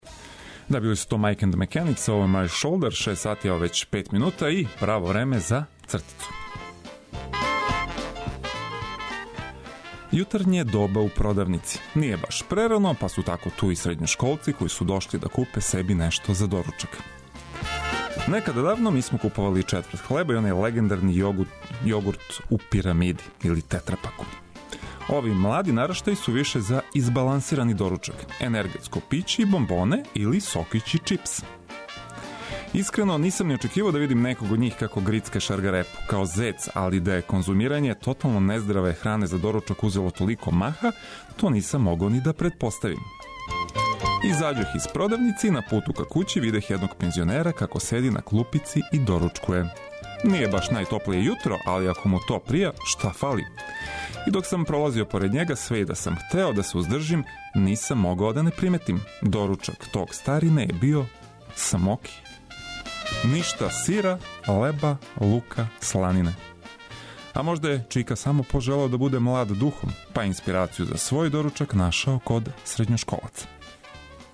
Било да се тешко будите, или лако скочите на ноге из  кревета, Устанак је ту да вас наоружа осмехом и најновијим информацијама за успешан почетак новог дана. Одлична музика је неопходни бонус!